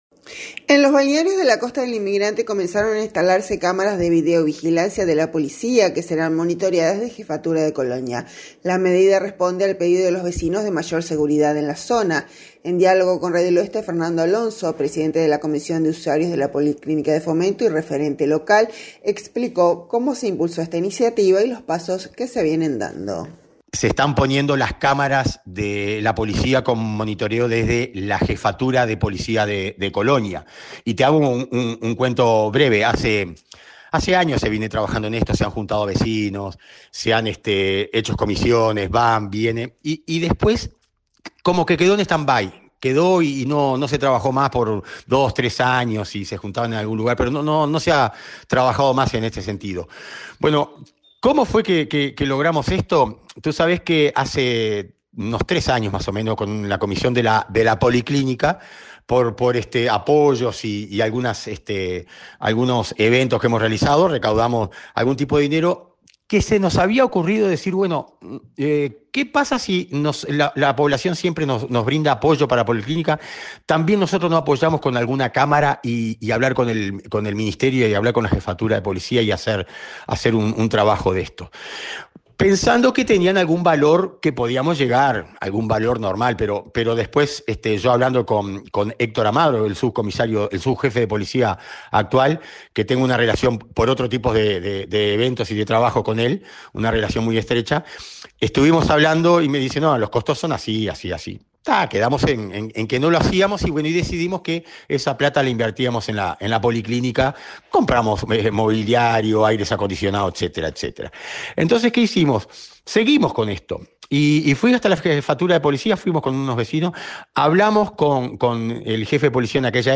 En diálogo con Radio del Oeste